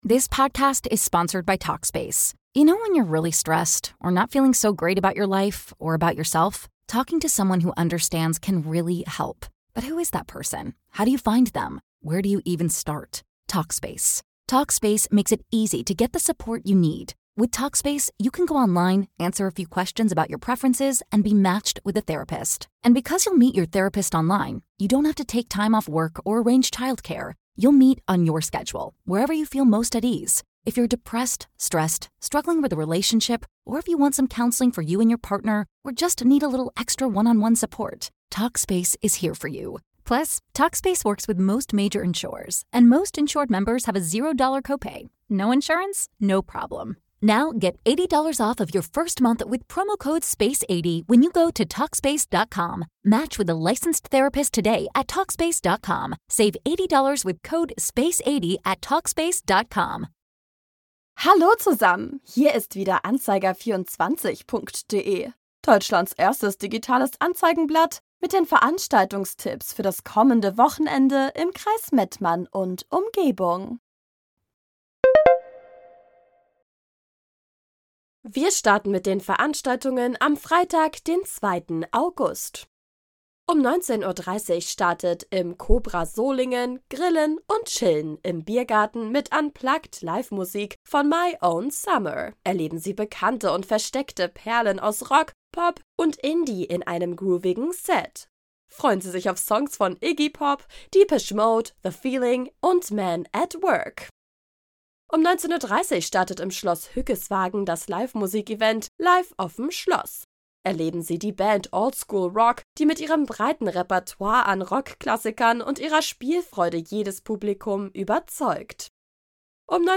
„Musik“